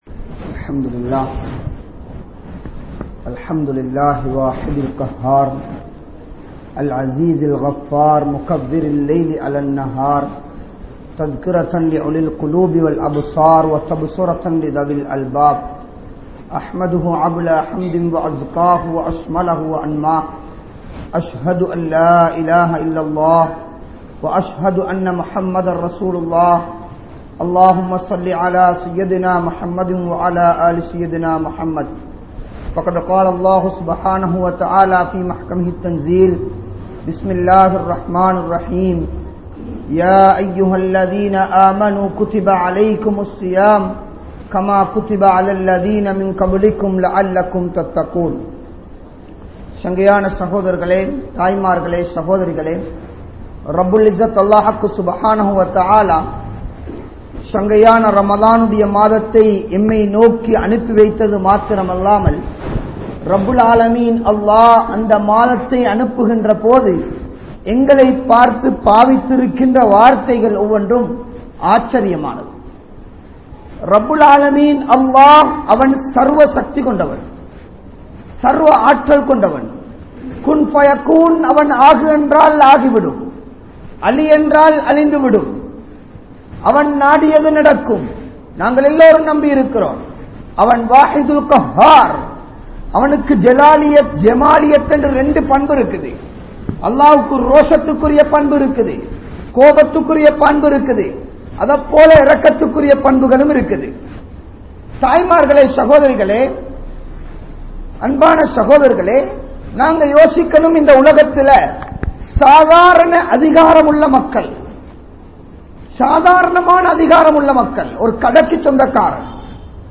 Ramalaanai Seeralikkaatheerhal (ரமழானை சீரழிக்காதீர்கள்) | Audio Bayans | All Ceylon Muslim Youth Community | Addalaichenai